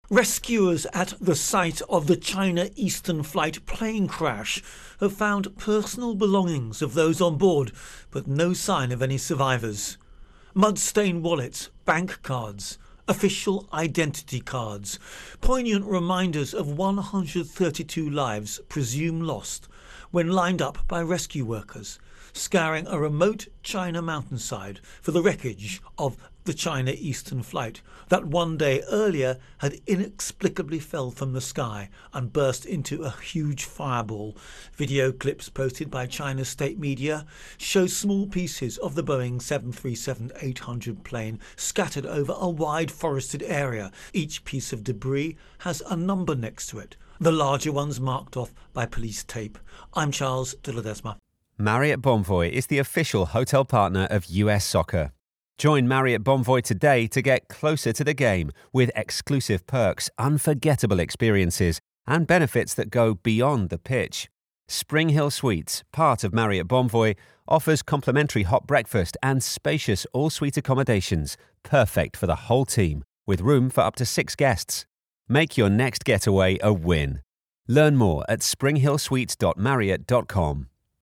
China Plane Crash Intro and Voicer